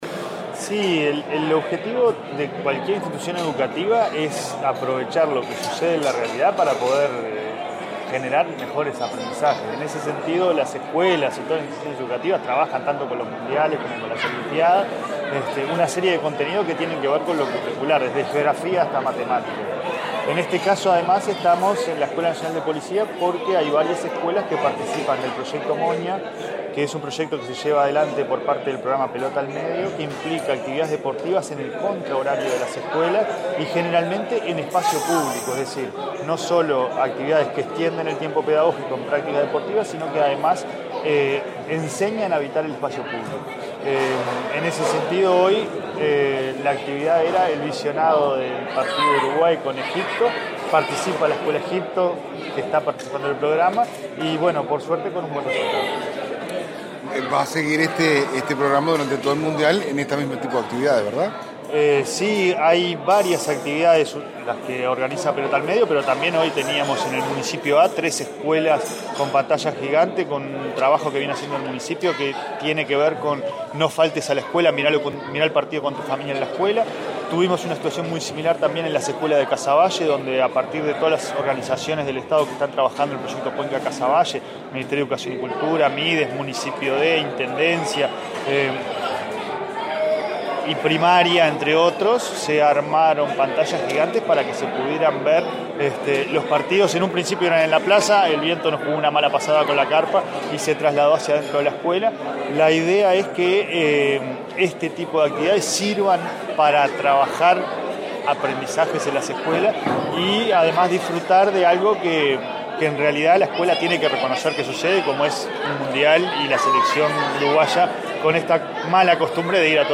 “El objetivo de cualquier institución educativa es aprovechar torneos deportivos para generar mejores aprendizajes”, señaló el consejero de Primaria Pablo Caggiani, en la denominada “Jornada de integración celeste”, enmarcada en el proyecto Moña del programa Pelota al Medio, que realiza actividades deportivas en el contra horario escolar. En esta ocasión, se miran los partidos de Uruguay en el Mundial.